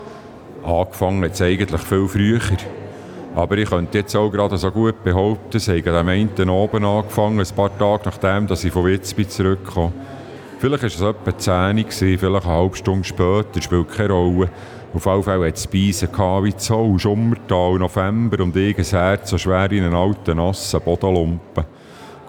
Listen to Lenz reading an extract from the novel to get a flavour of the language.